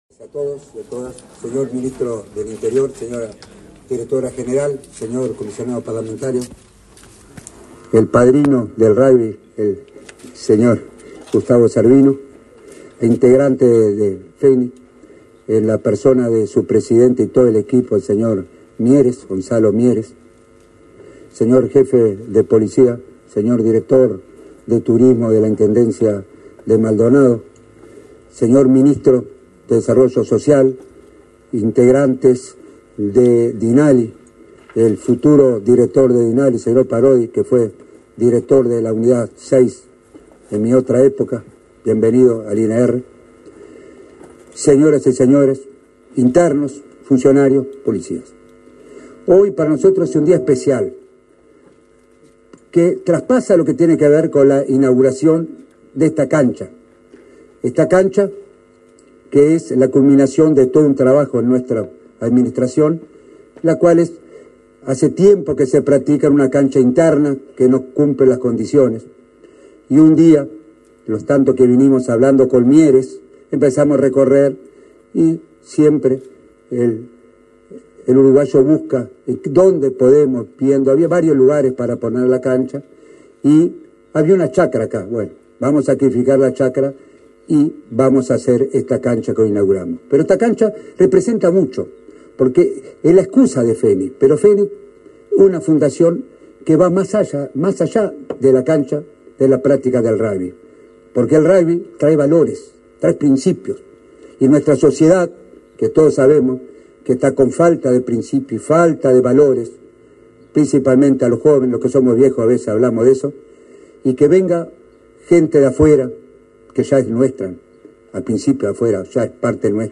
Palabras del director del INR, Luis Mendoza
Con la presencia del ministro del Interior, Nicolás Martinelli, se inauguró, este 25 de febrero, una cancha de rugby en el centro carcelario Las Rosas
En el acto disertó el director del Instituto Nacional de Rehabilitación (INR), Luis Mendoza.